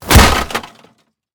metal2.ogg